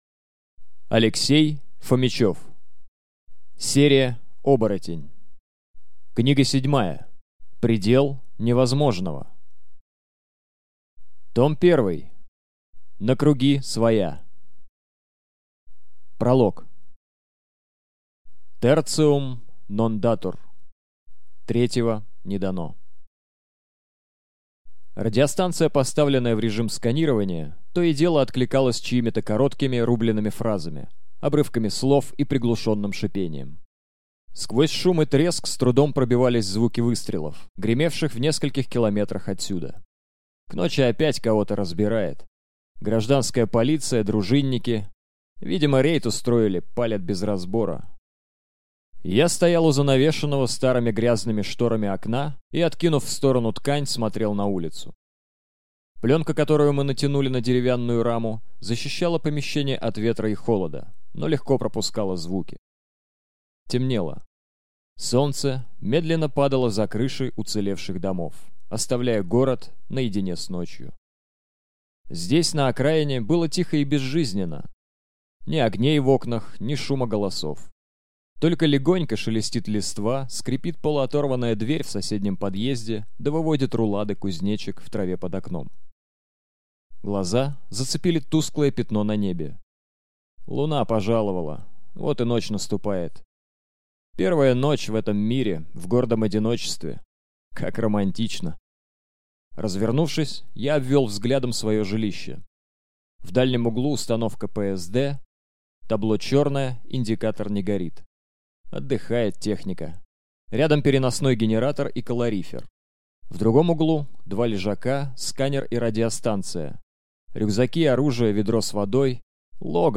Скачать, слушать онлайн аудиокнигу Предел невозможного